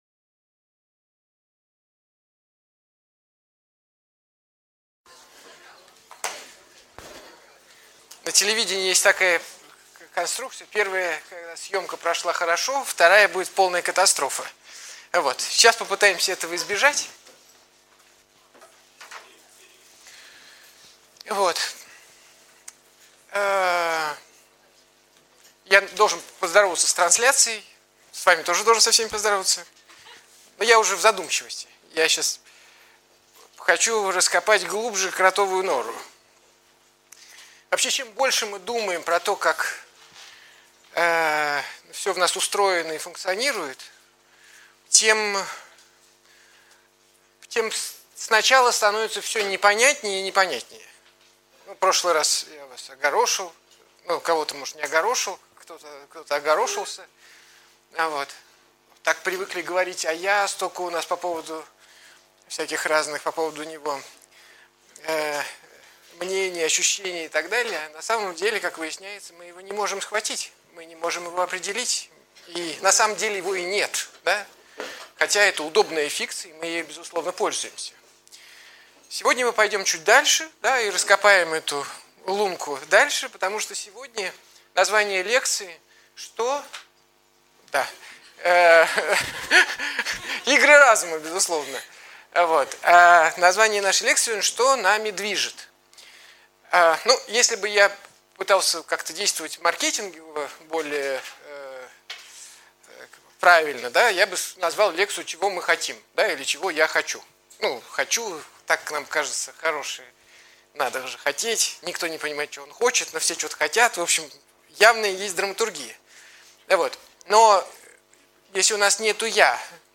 Aудиокнига Лекция №2 «Что мною движет?» Автор Андрей Курпатов Читает аудиокнигу Андрей Курпатов.